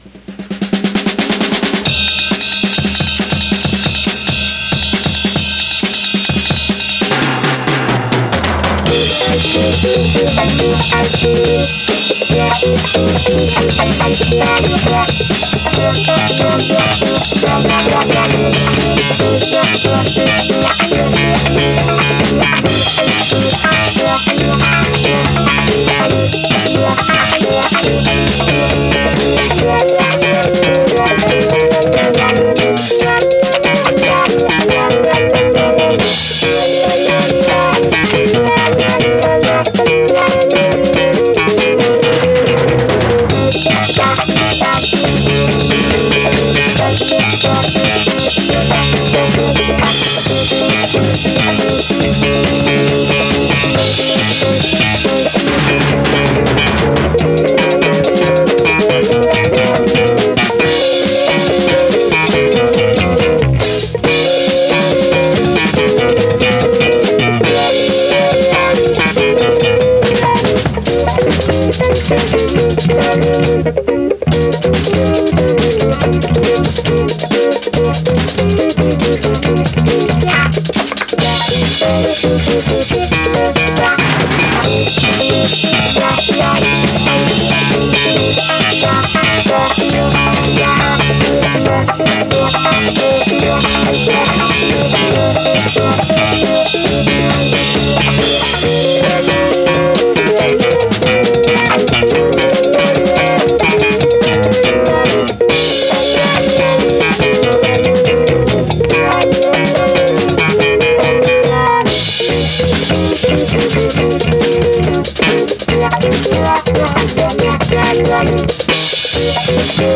Recorded in a concrete shed in Lake Oaks back in 1989
guitar
drums